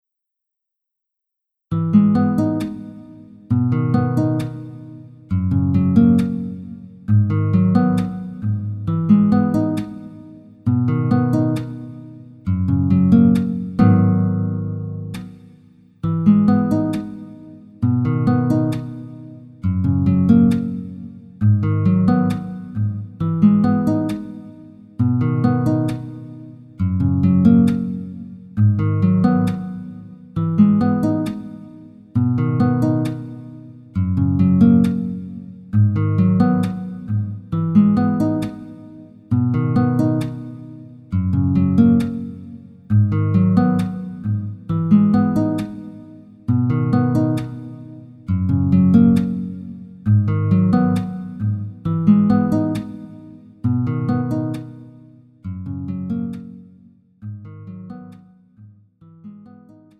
음정 -1키 3:08
장르 가요 구분 Pro MR